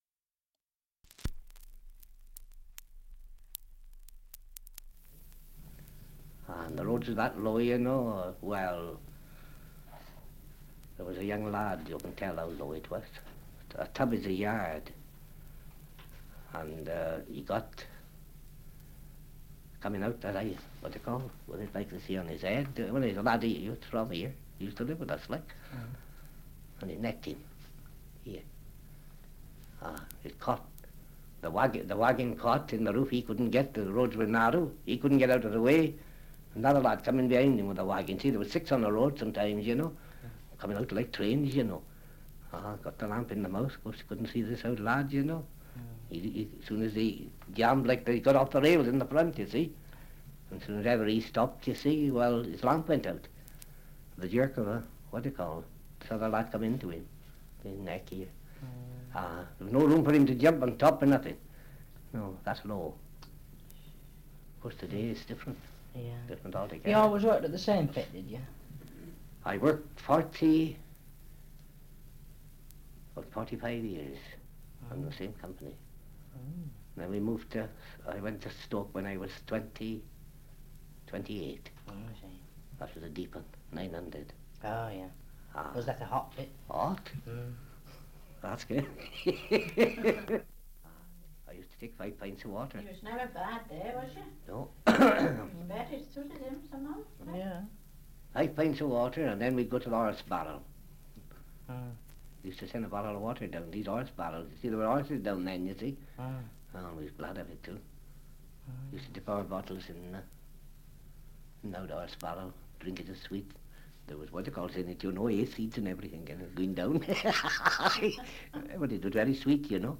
Survey of English Dialects recording in Weston Rhyn, Shropshire
2 - Survey of English Dialects recording in Weston Rhyn, Shropshire
78 r.p.m., cellulose nitrate on aluminium